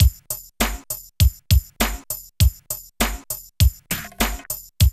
22 DRUM LP-R.wav